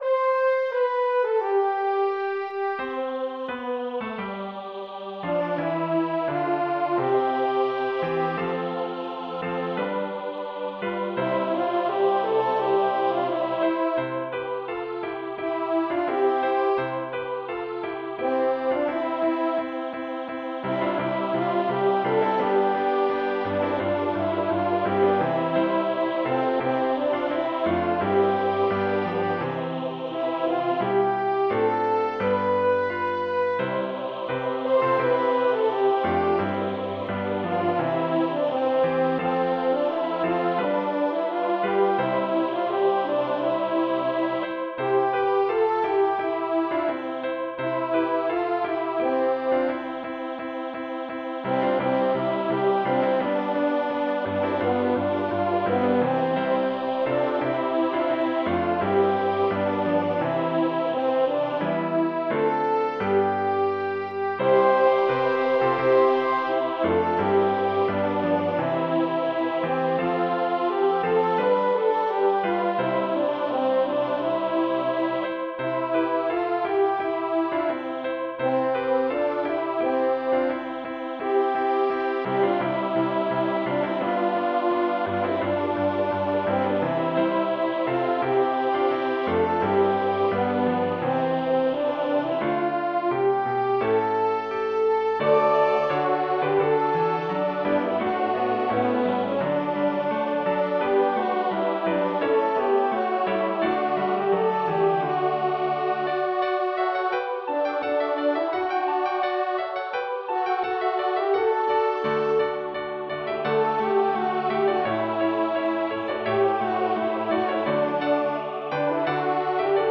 For this arrangement I put the choir in the key of C for the first 3 verses with a transposition to D for the fourth verse. Each verse has a two measure introduction (or transition) before the verse. The unison phrase for the first verse is the only variance from the hymn book for the choir. The tinkering of the accompaniment is only in the fourth verse.
The French Horn part is in the concert pitch in the full score.
Voicing/Instrumentation: SATB We also have other 60 arrangements of " Joy to the World ".